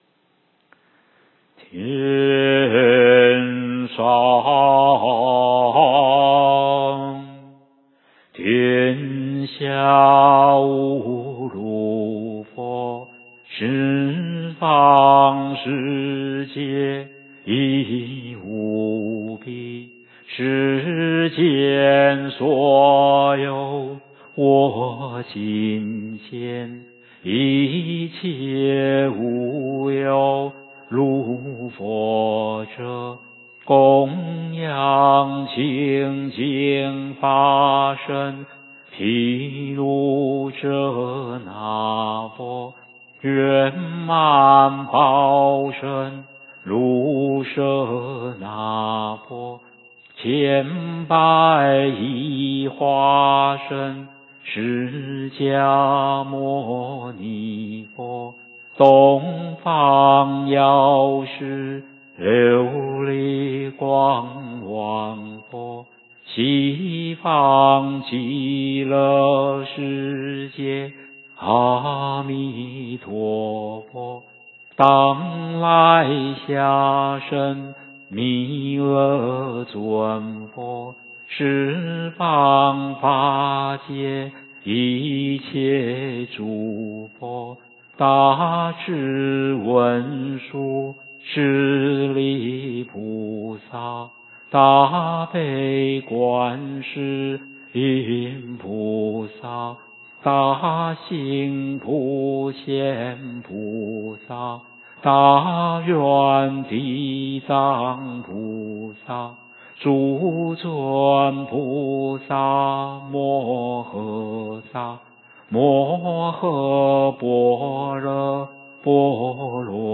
梵呗教学音档
二时临斋．午斋(三德)(有法器)